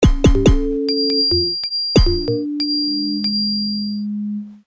In questo boxxetto qua di fianco.. troveremo dei loopz fatti da me con un programmino spettacolare... che si chiama Fruity Loops versione 3.4